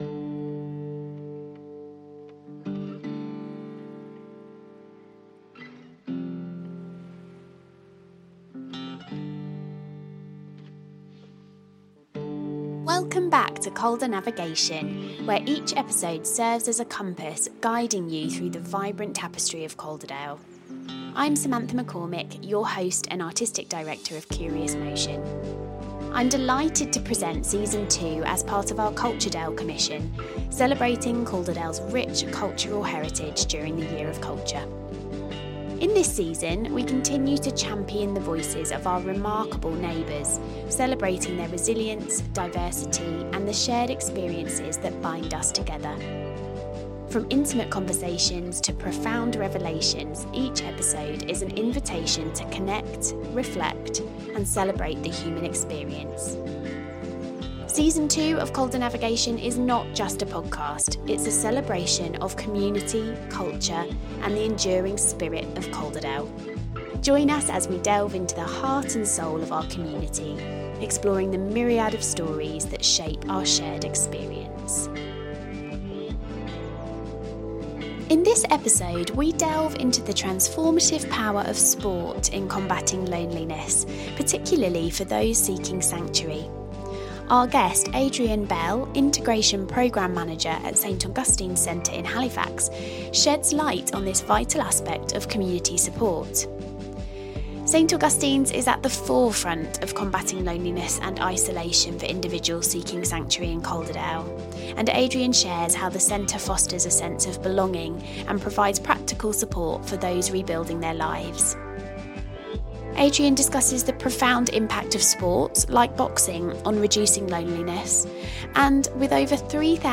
We recorded this episode during a boxing session at Star Boxing Club in Halifax, a regular session making boxing and exercise accessible to the refugee community.